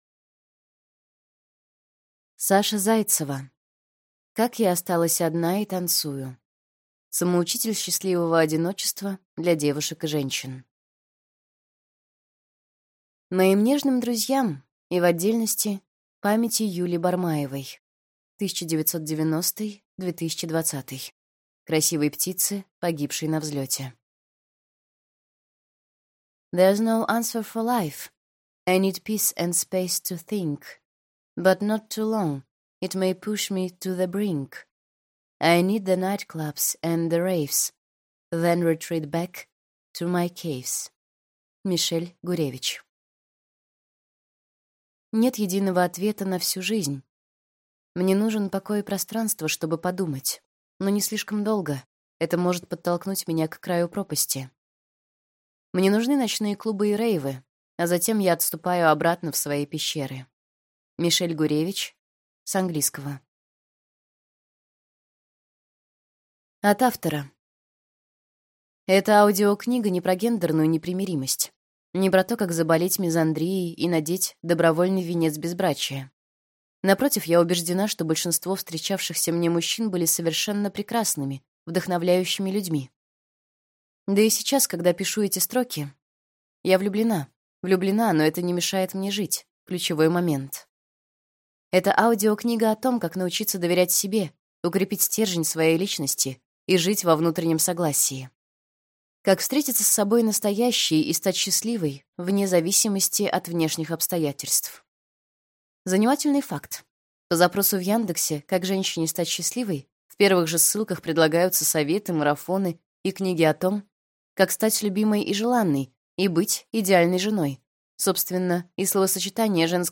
Аудиокнига Как я осталась одна и танцую. Первый самоучитель счастливого одиночества для девушек и женщин | Библиотека аудиокниг